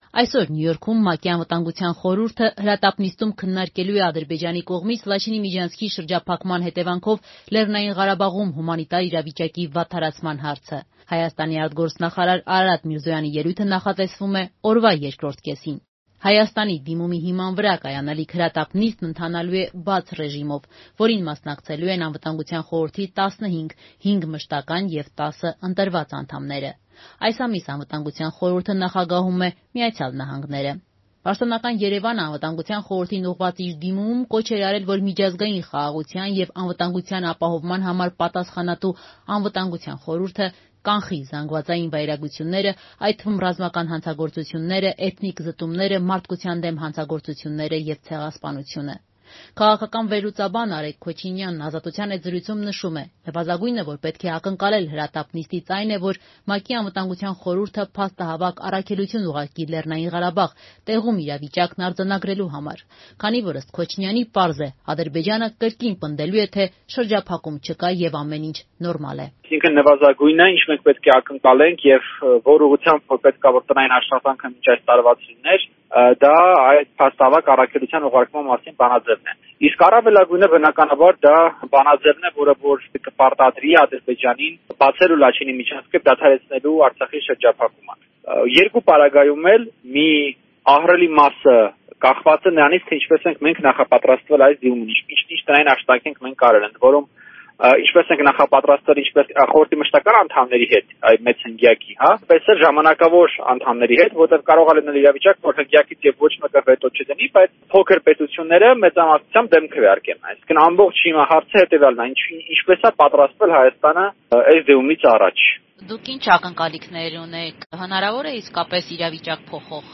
Ռեպորտաժներ
Նվազագույն ակնկալիքը պետք է լինի ԼՂ փաստահավաք առաքելություն ուղարկման մասին բանաձևի ընդունումը. քաղաքական մեկնաբան